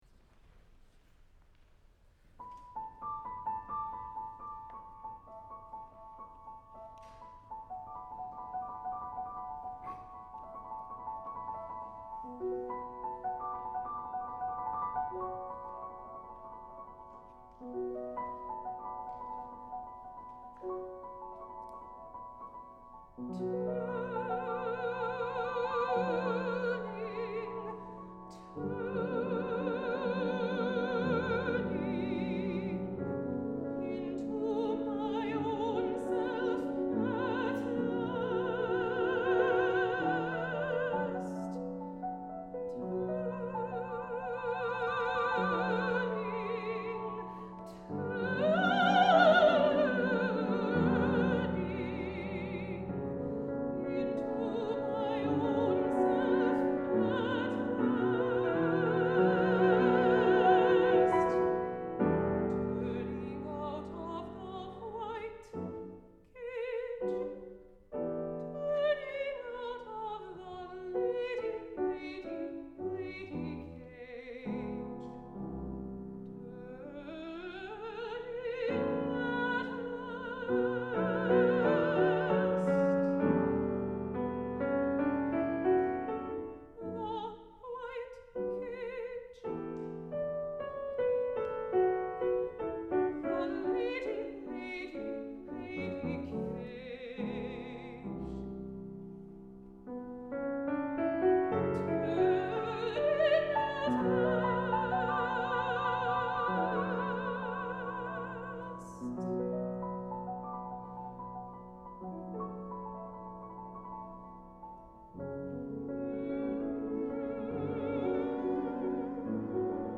for Mezzo-soprano and Piano (1997)